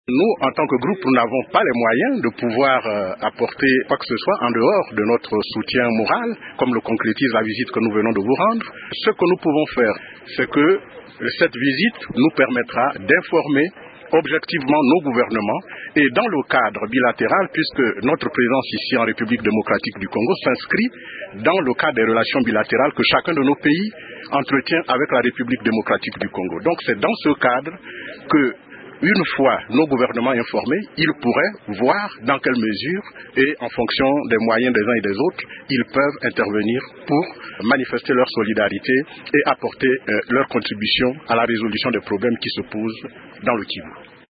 Le diplomate gabonais répond :